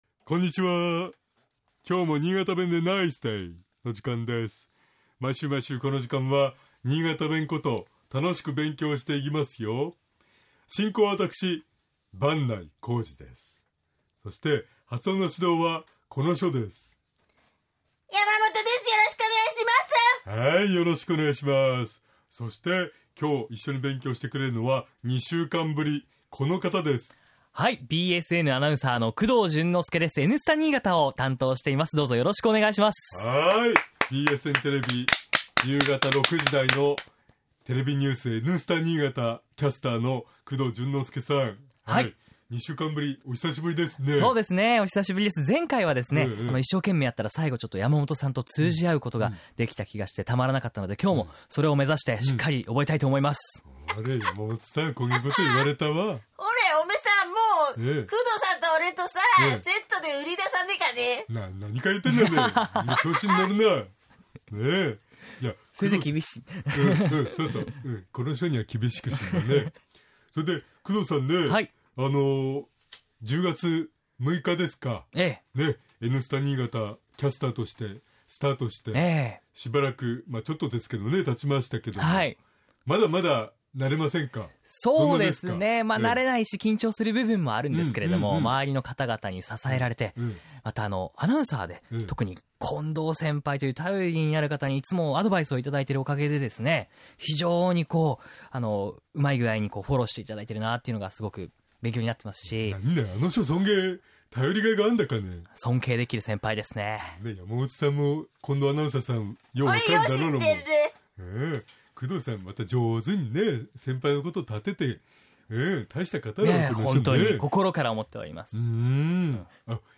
（スキット）